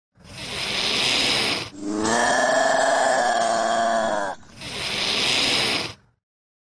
Злой рык степного каракала (Caracal caracal)